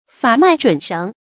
法脈準繩 注音： ㄈㄚˇ ㄇㄞˋ ㄓㄨㄣˇ ㄕㄥˊ 讀音讀法： 意思解釋： 猶言法則標準。